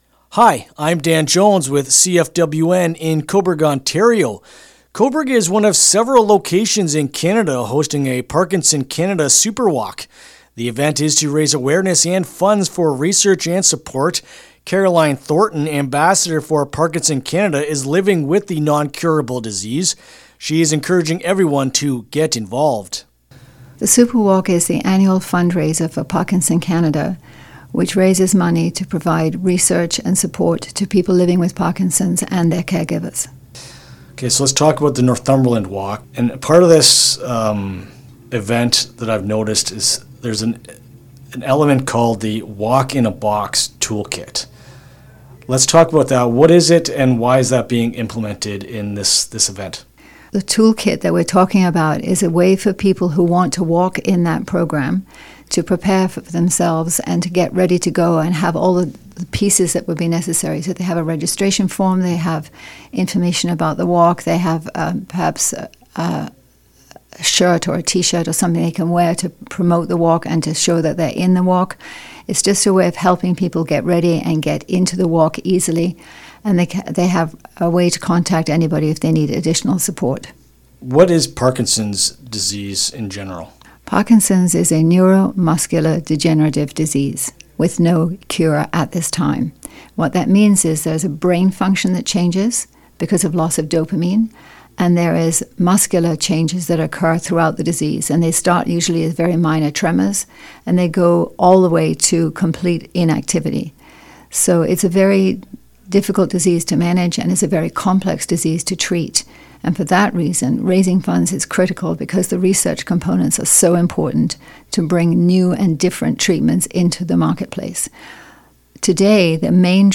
Parkinson-Superwalk-Interview-LJI.mp3